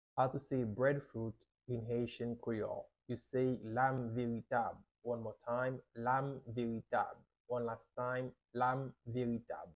How to say “Breadfruit” in Haitian Creole - “Lam veritab” pronunciation by a native Haitian Teacher
“Lam veritab” Pronunciation in Haitian Creole by a native Haitian can be heard in the audio here or in the video below: